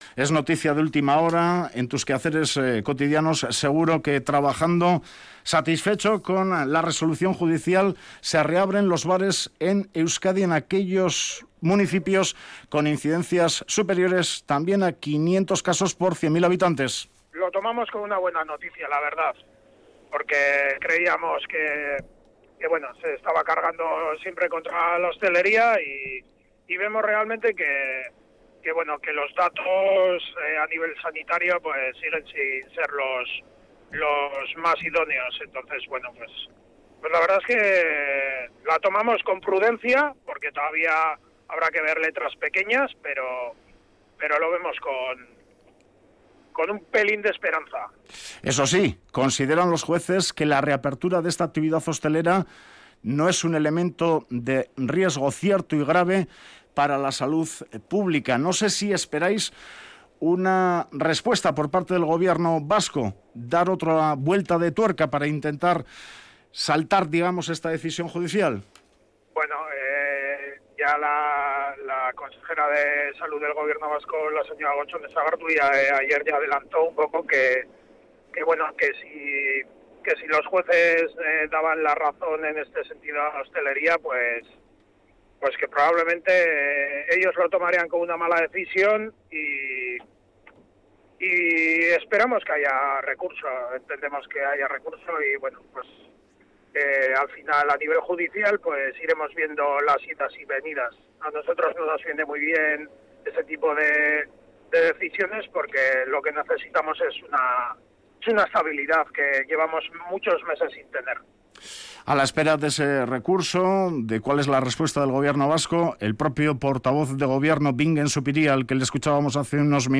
entrevistado en Radio Vitoria